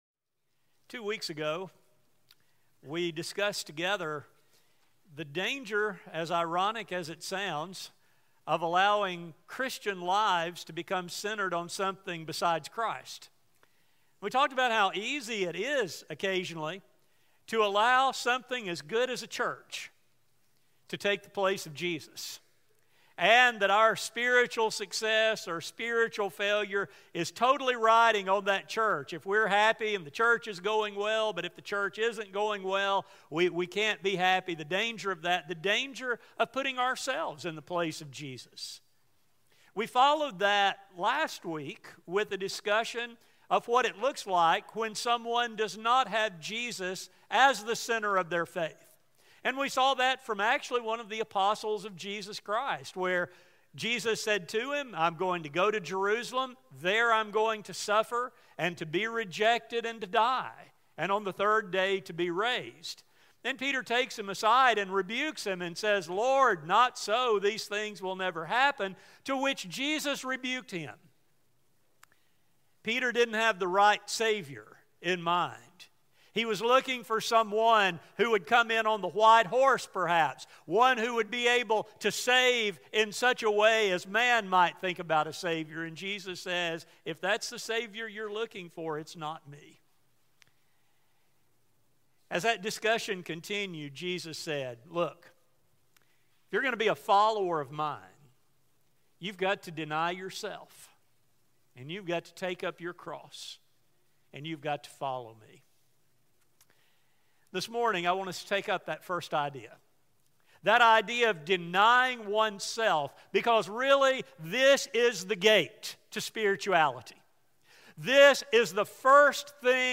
This study will focus on God’s great gift to humanity, what self-denial means, and suggestions for how this can become a way of life. A sermon recording